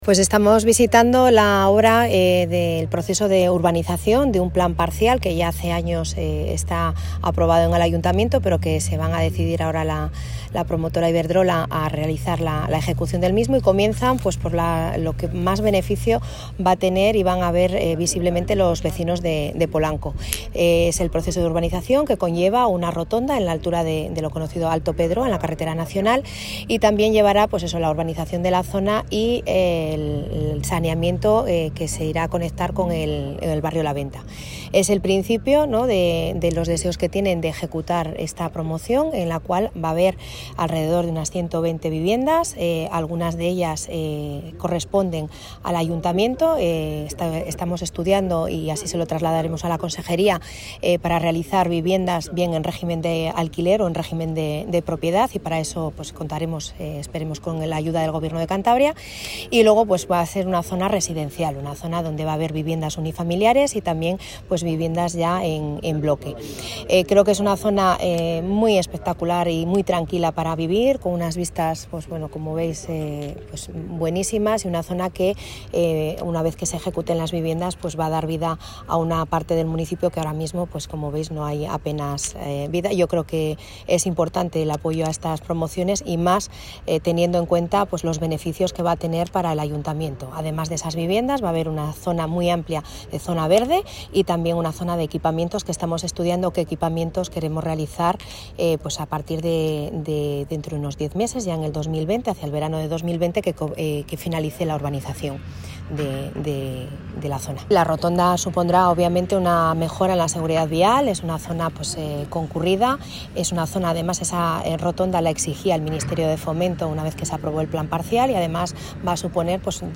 Alcaldesa-inicio-obras-de-rotonda-en-Alto-Pedroa.mp3